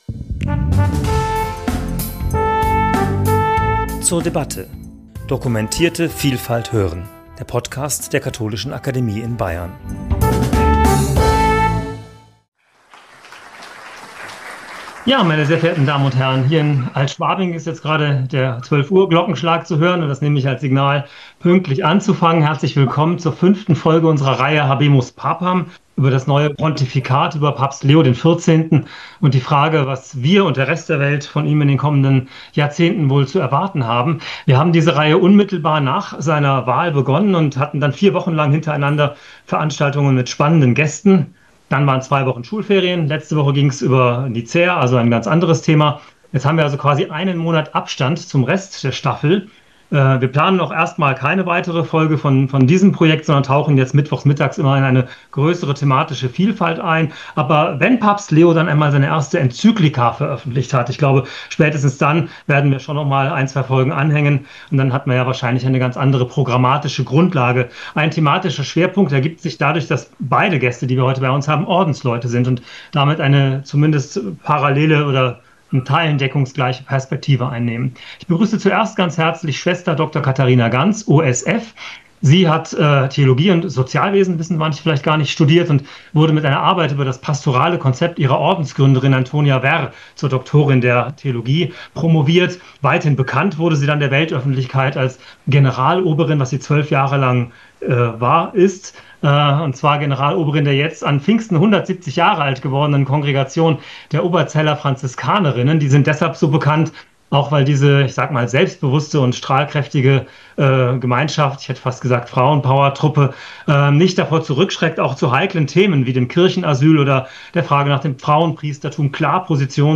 Gespräch zum Thema 'Habemus papam! Ecclesia, quo vadis?', Folge 5 ~ zur debatte Podcast